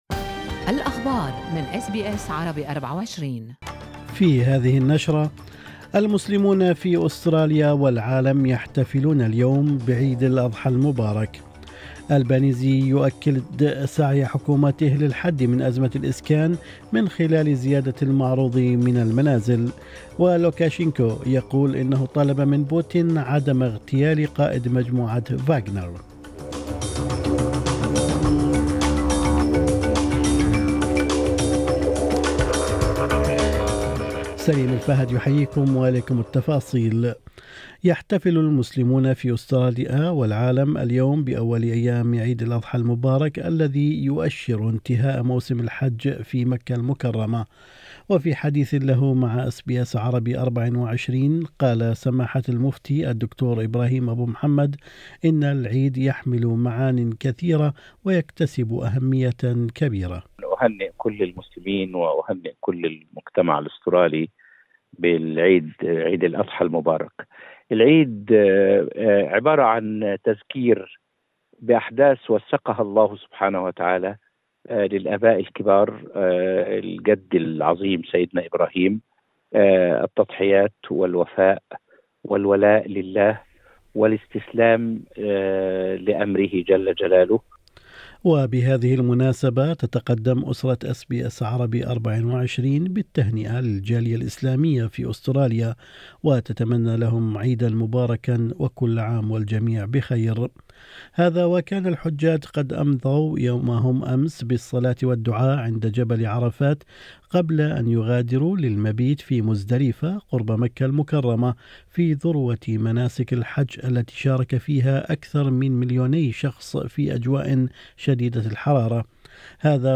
نشرة أخبار الصباح 28/6/2023